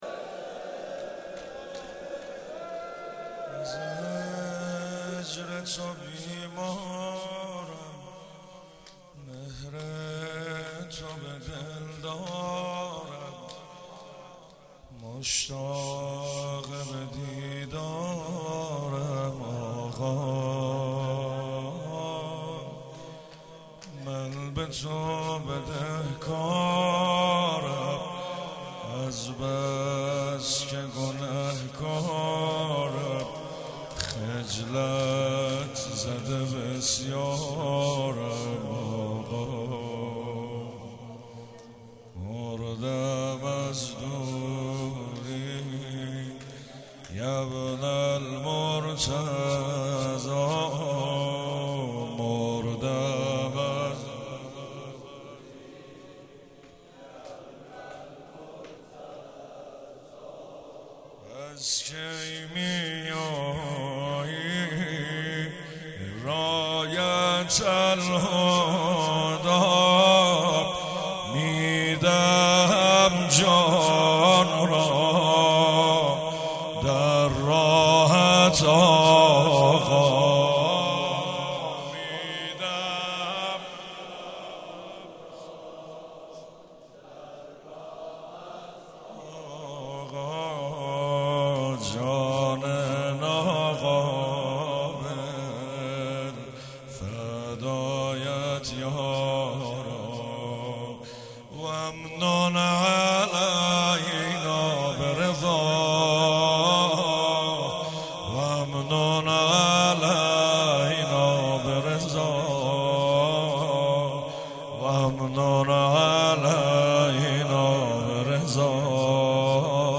اجرا شده در هیئت علمدار مشهد
شور